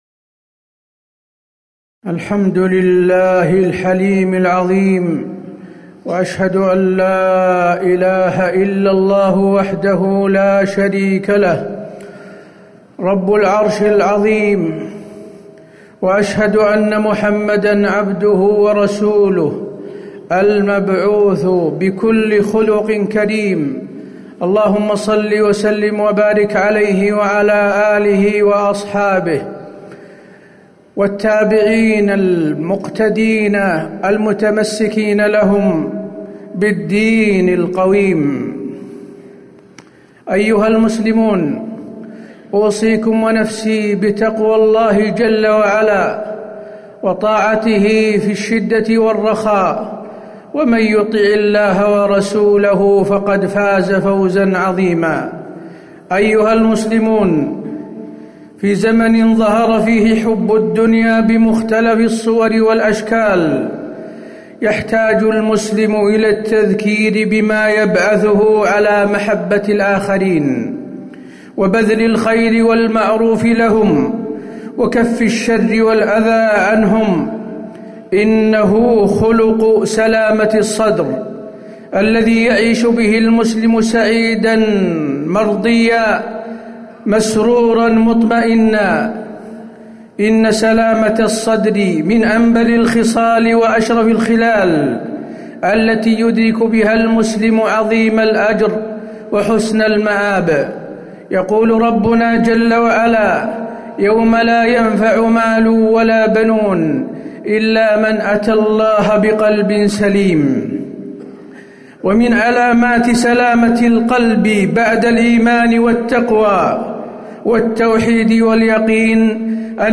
تاريخ النشر ٢٩ ربيع الأول ١٤٤٠ هـ المكان: المسجد النبوي الشيخ: فضيلة الشيخ د. حسين بن عبدالعزيز آل الشيخ فضيلة الشيخ د. حسين بن عبدالعزيز آل الشيخ سلامة الصدر